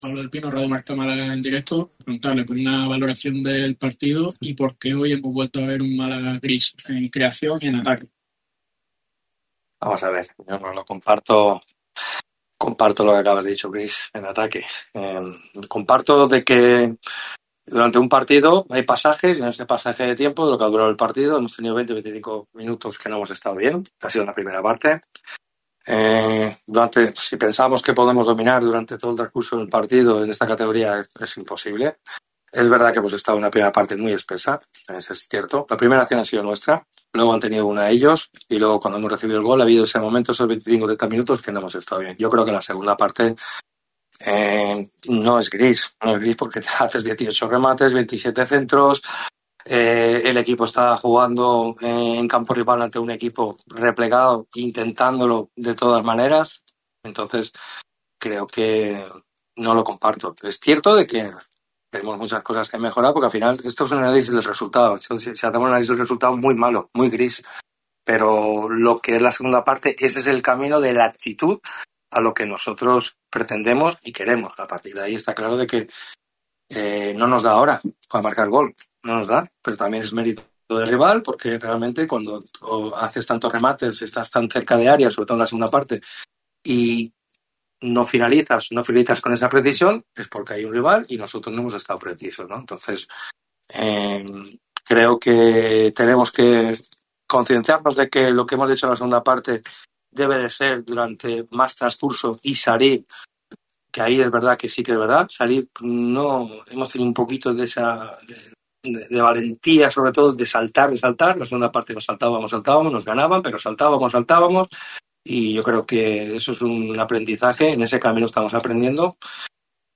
El técnico de Nules compareció ante los medios de comunicación al término del Málaga CF – Cádiz CF relativo a la sexta jornada de LaLiga Hypermotion que se resolvió con victoria por la mínima para los de Gaizka Garitano.